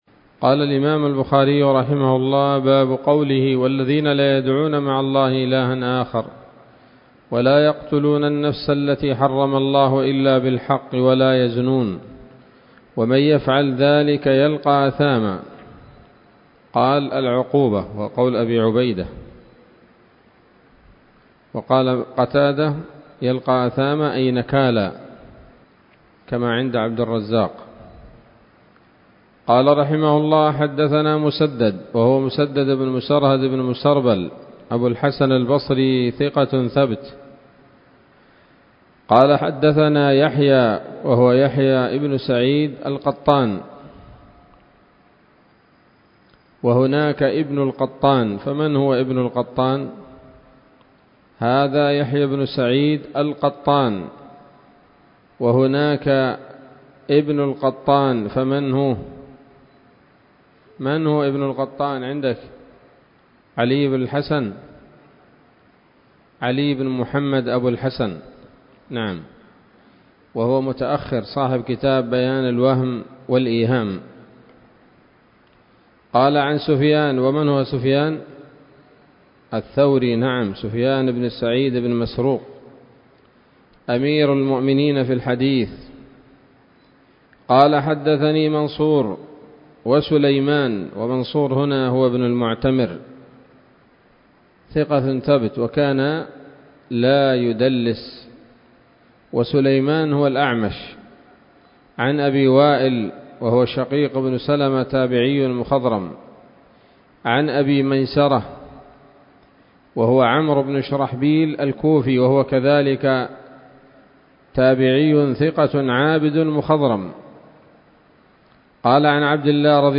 الدرس التاسع والثمانون بعد المائة من كتاب التفسير من صحيح الإمام البخاري